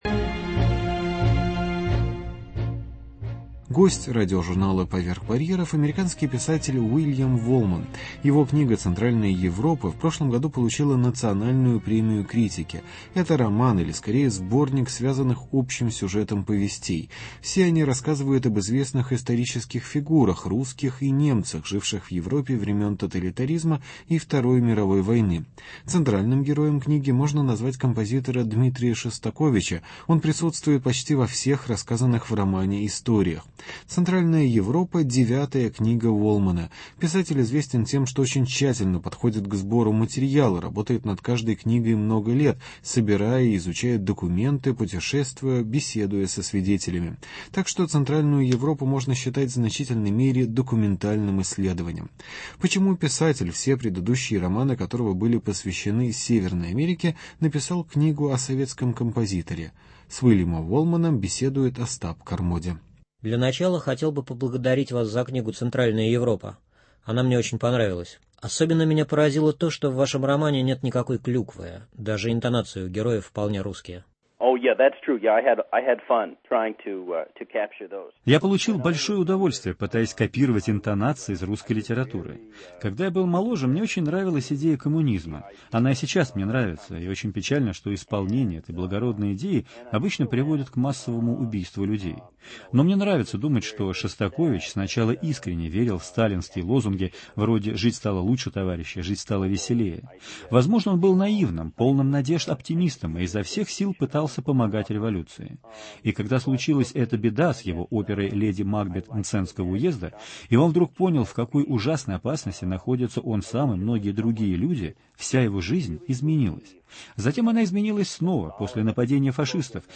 Интервью